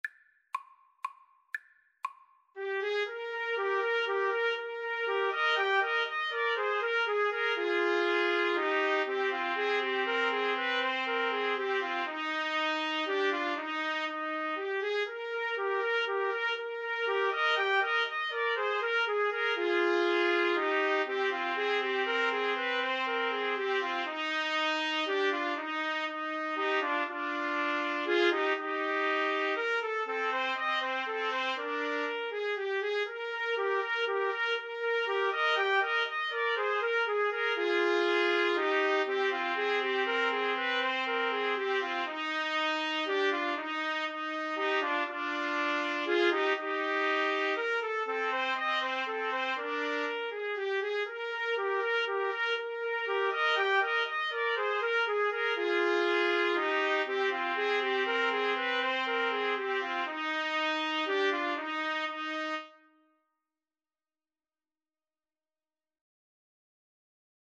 3/4 (View more 3/4 Music)
Classical (View more Classical Trumpet Trio Music)